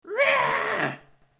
Tiếng Gầm Tức Giận… giọng nữ, hoạt hình
Thể loại: Hiệu ứng âm thanh
Description: "Tiếng gầm tức giận" với giọng nữ hoạt hình còn có thể gọi là tiếng rống ngắn, tiếng thét, tiếng gào hay tiếng hét mạnh mẽ. Hiệu ứng âm thanh này mang sắc thái kịch tính, dữ dội, thường được sử dụng trong các cảnh cao trào để nhấn mạnh cảm xúc tức giận hoặc quyền lực.
tieng-gam-tuc-gian-giong-nu-hoat-hinh-www_tiengdong_com.mp3